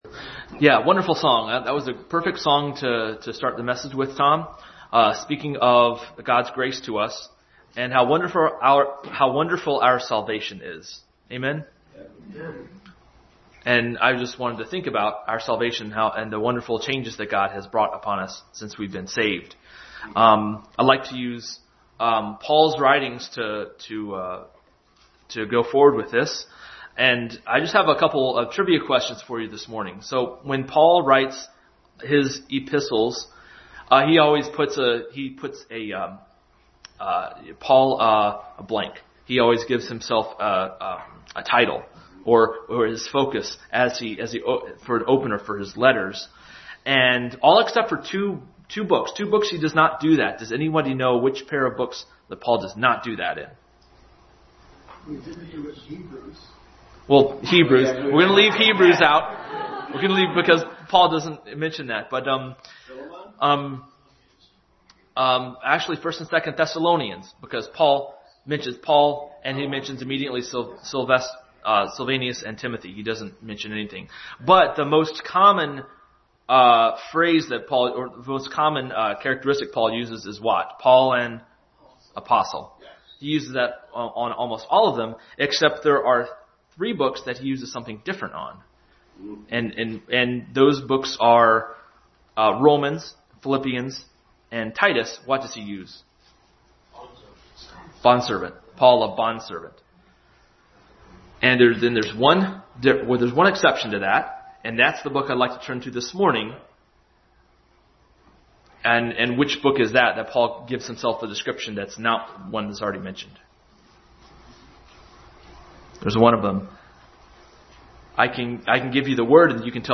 Passage: Philemon, John 3:3 Service Type: Family Bible Hour